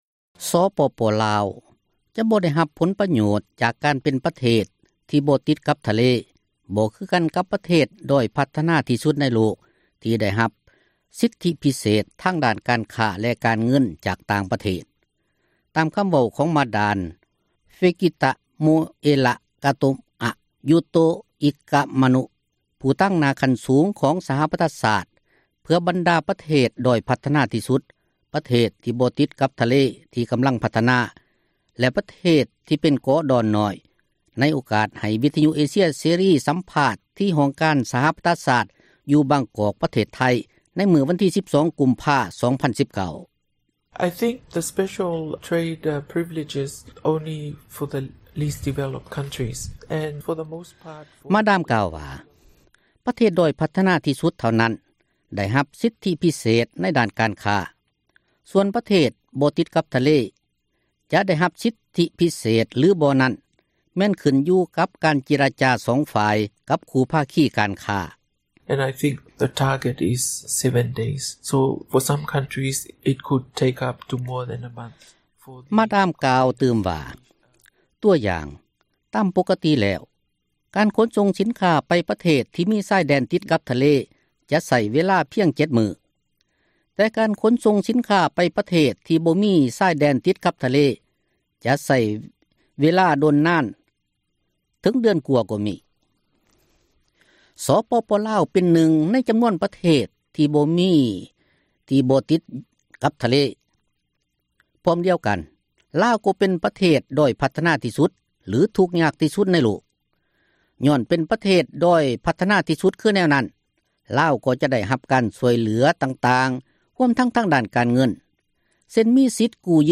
ສປປລາວ ຈະບໍ່ໄດ້ຮັບຜົລປະໂຫຍດ ຈາກການເປັນປະເທດ ທີ່ບໍ່ຕິດກັບທະເລ, ບໍ່ຄືກັນກັບປະເທດດ້ອຍພັທນາທີ່ສຸດໃນໂລກ, ທີ່ໄດ້ຮັບ ສິດທິພິເສດທາງດ້ານການຄ້າ ແລະການເງິນ ຈາກຕ່າງປະເທດ. ຕາມຄຳເວົ້າຂອງ ມາດາມ ເຟກິຕະໂມເອລະ ກາໂຕອະ ຢູໂຕອິກະມະນຸ (Fekitamoela Katoa ‘Utoikamanu) ຜູ້ຕາງໜ້າຂັ້ນສູງຂອງສະຫະປະຊາຊາດ, ເພື່ອບັນດາປະເທດດ້ອຍພັທນາທີ່ສຸດ, ປະເທດບໍ່ຕິດ ກັບທະເລ ທີ່ກຳລັງພັທນາ, ແລະປະເທດທີ່ເປັນເກາະດອນນ້ອຍ, ໃນໂອກາດໃຫ້ ວິທຍຸ ເອເຊັຍເສຣີ ສຳພາດທີ່ຫ້ອງການສະຫະປະຊາຊາດ ຢູ່ບາງກອກ ປະເທດໄທ ໃນມື້ວັນທີ 12 ກຸມພາ 2019: